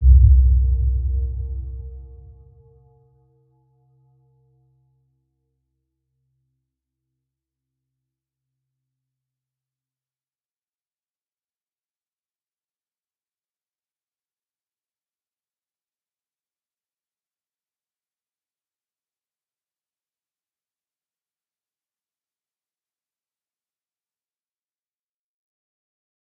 Round-Bell-C2-mf.wav